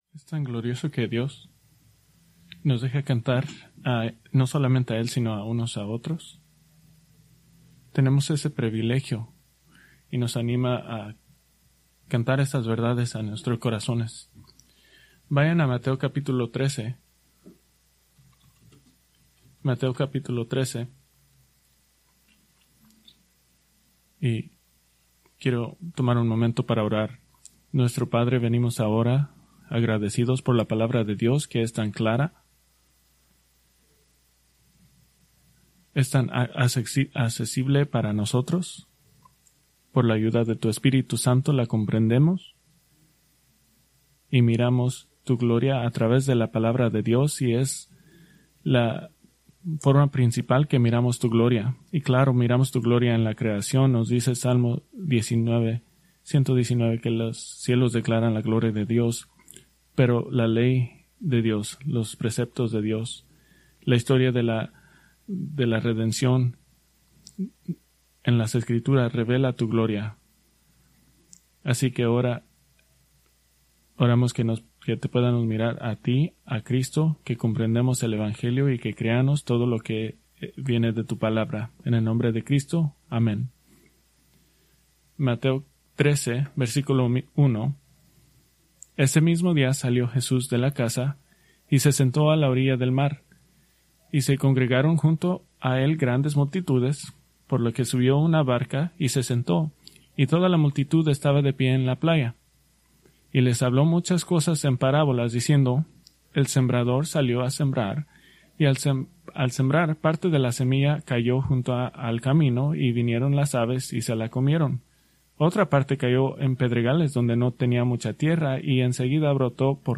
Preached February 8, 2026 from Mateo 13:18-23